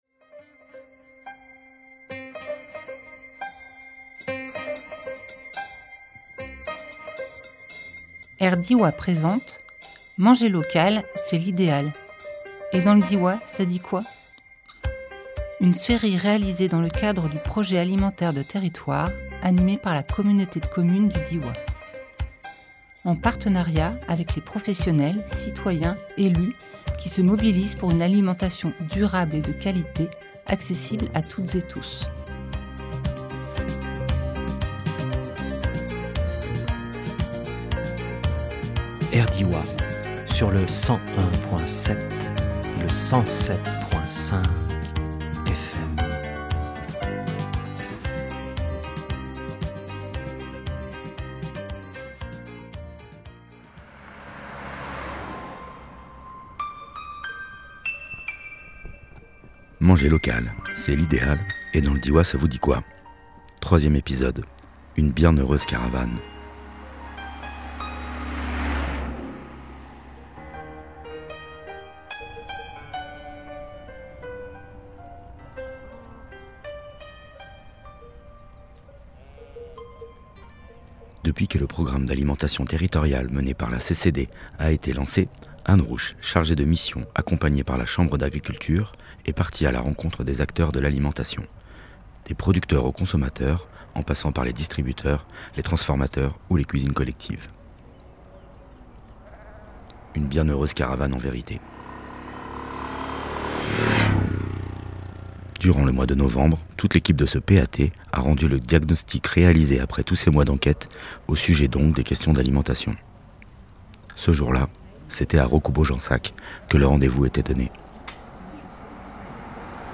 Une bien heureuse caravane en vérité Durant le mois de novembre, toute l’équipe de ce PAT a rendu le diagnostic réalisé après tous ces mois d’enquête au sujet donc des questions d’alimentation. Ce jour là c’était à Recoubeau Jansac que le rendez vous était donné. L’alimentation locale et de qualité doit être en effet accessible à tous et toutes.